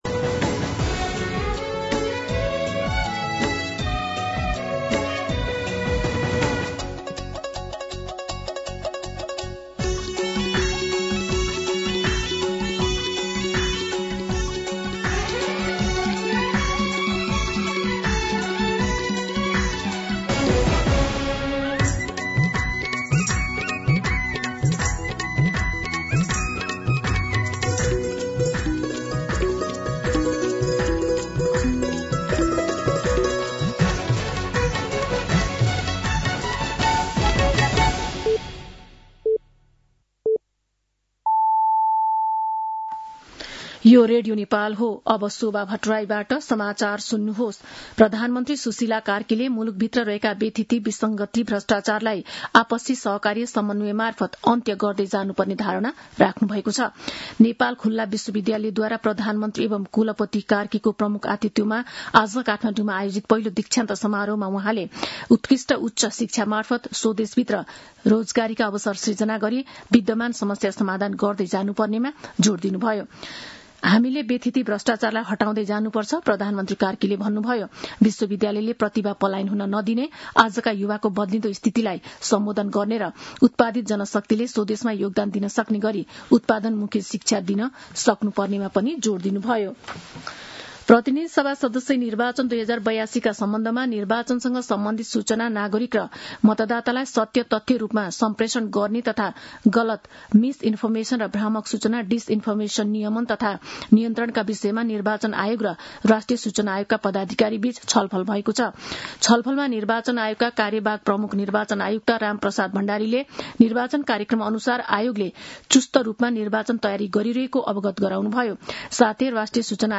दिउँसो ४ बजेको नेपाली समाचार : ११ माघ , २०८२
4-pm-News-10-11.mp3